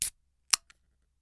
HOT缶コーヒータブ開
open_canned_coffee.mp3